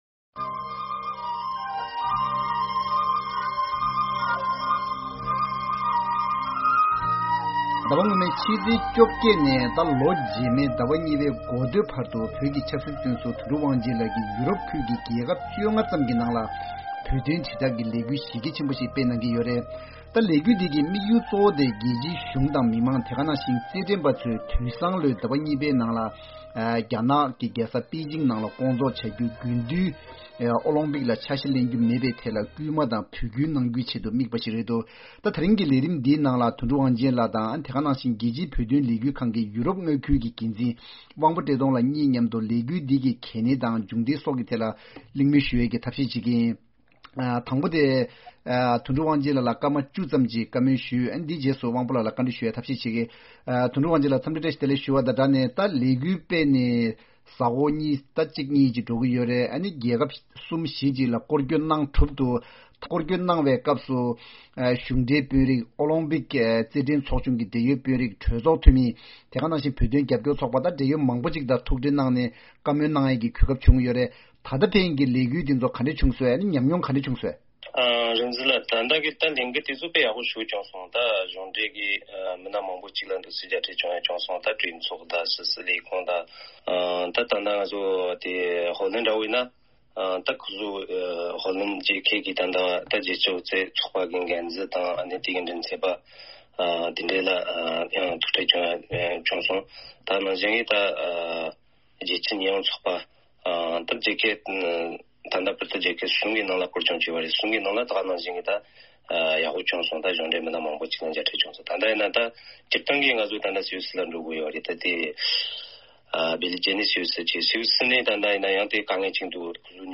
བོད་ཀྱི་ཆབ་སྲིད་བཙོན་ཟུར་དོན་གྲུབ་དབང་ཆེན་ལགས་ཀྱིས་ཡོ་རོབ་ཁུལ་གྱི་རྒྱལ་ཁབ་༡༥ ་ནང་བོད་དོན་དྲིལ་བསྒྲགས་ཀྱི་ལས་འགུལ་སྤེལ་བཞིན་ཡོད་ཅིང་། ཐེངས་འདིའི་བགྲོ་གླེང་མདུན་ཅོག་ལས་རིམ་ནང་དོན་གྲུབ་དབང་ཆེན་ལགས་ཀྱི་ལས་འགུལ་གྱི་དམིགས་དོན་དང་ད་ཕན་གྱི་མྱོང་བ་ཇི་བྱུང་སོགས་ཀྱི་ཐད་གླེང་མོལ་ཞུས་ཡོད།